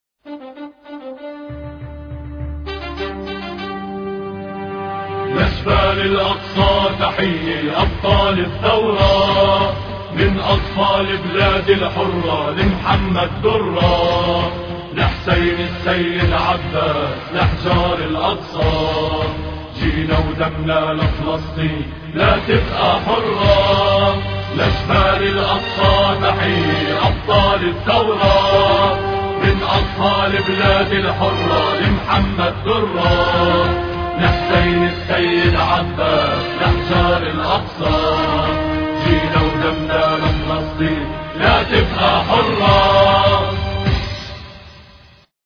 لأشبال الأقصى الثلاثاء 6 فبراير 2007 - 00:00 بتوقيت طهران تنزيل الحماسية شاركوا هذا الخبر مع أصدقائكم ذات صلة الاقصى شد الرحلة أيها السائل عني من أنا..